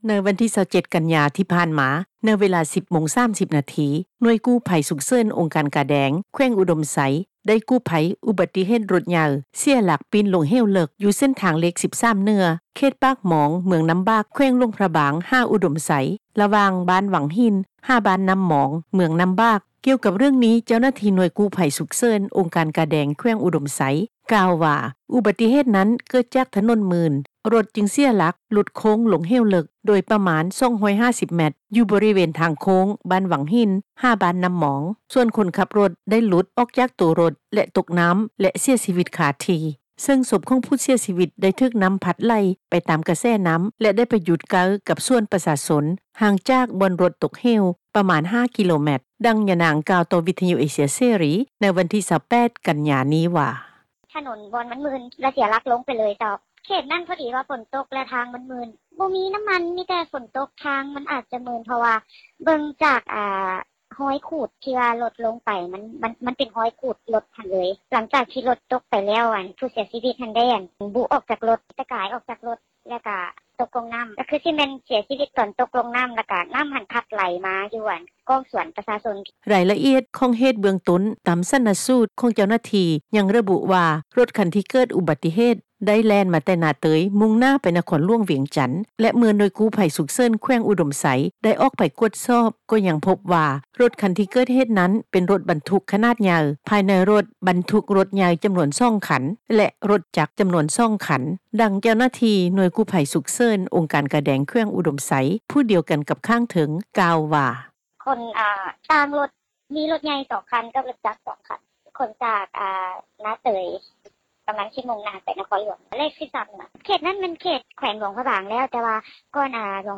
ດັ່ງ ຍານາງກ່າວຕໍ່ ວິທຍຸເອເຊັຽເສຣີ ໃນວັນທີ 28 ກັນຍາ ນີ້ວ່າ:
ດັ່ງ ໂຊເຟິຣ໌ຂັບຣົຖຮັບຈ້າງ ກ່າວໃນມື້ດຽວກັນນີ້ວ່າ:
ດັ່ງ ຊາວບ້ານ ແຂວງອຸດົມໄຊ ກ່າວໃນມື້ດຽວກັນນີ້ວ່າ: